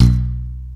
27. 27. Percussive FX 26 ZG